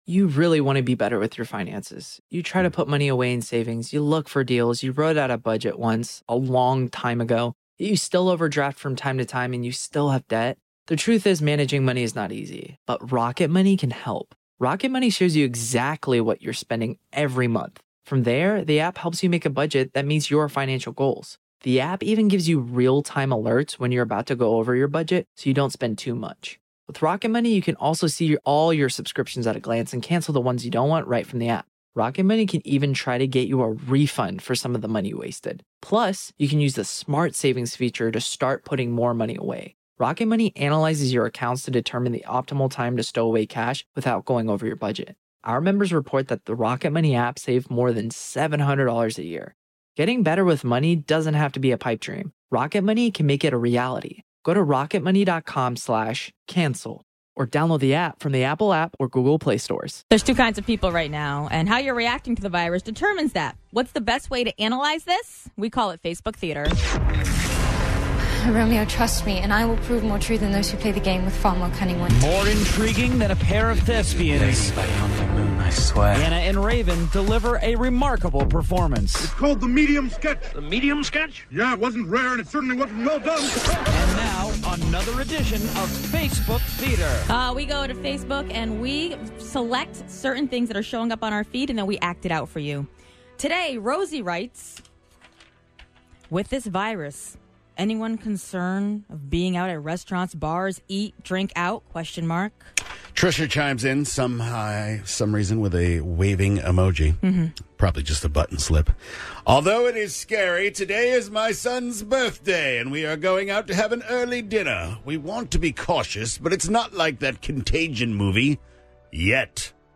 they act it out for you!